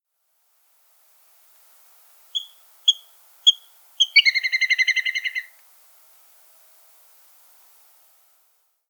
Mergullón pequeno
Canto